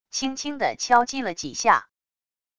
轻轻的敲击了几下wav音频